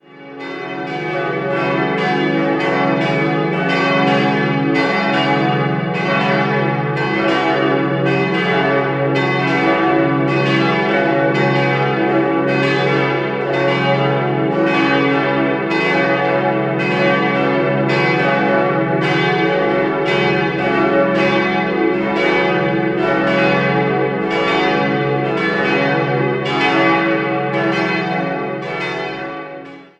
4-stimmiges Geläut: cis'-e'-fis'-gis' D ie zweitgrößte Glocke stammt noch aus dem ursprünglichen Geläut und wurde 1899 von Franz Schilling in Apolda gegossen. Nach dem Verlust der restlichen Glocken im Zweiten Weltkrieg ergänzte die Firma Rincker in Sinn 1953/54 das Geläut wieder zu einem vierstimmigen Ensemble.